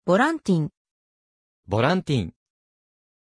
Pronunciation of Vallentin
pronunciation-vallentin-ja.mp3